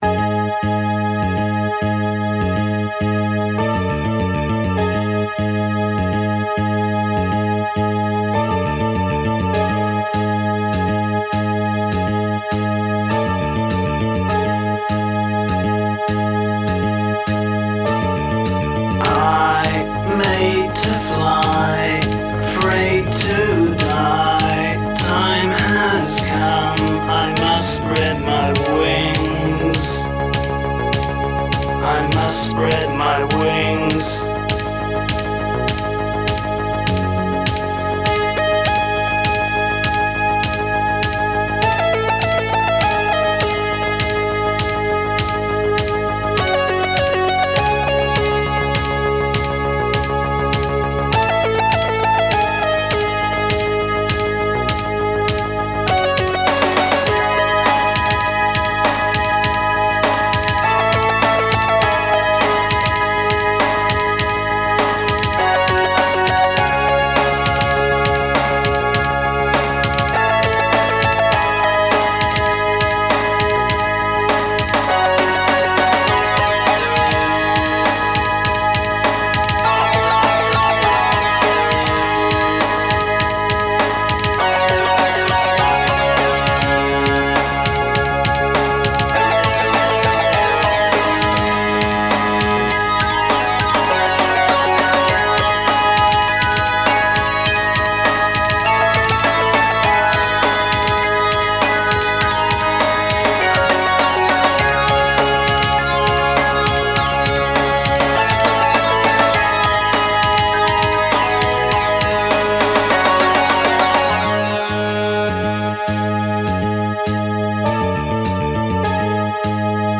Recorded AAD - Analogue Analogue Digital